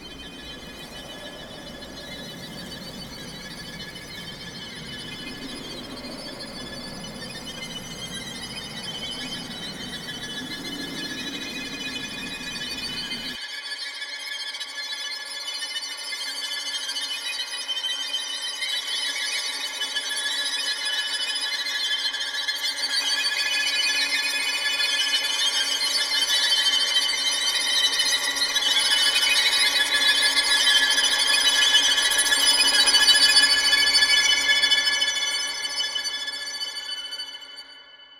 Horror_ViolinFX.ogg